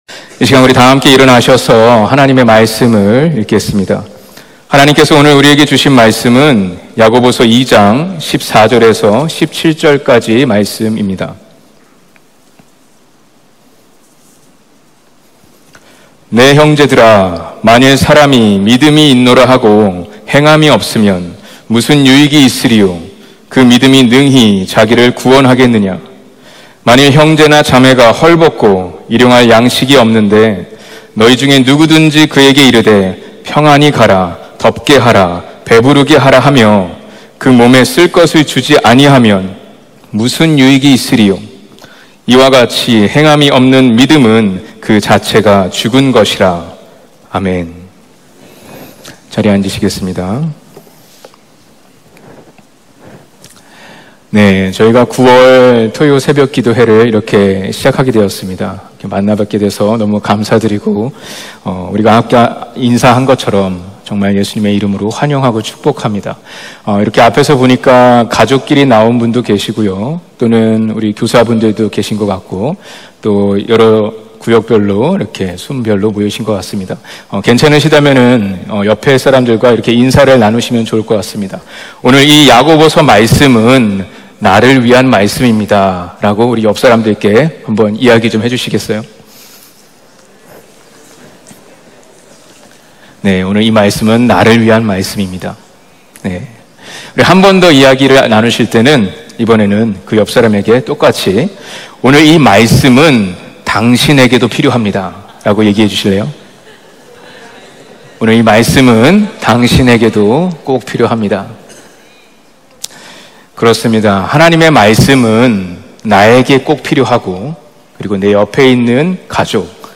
예배: 토요 새벽
토요 새벽 기도회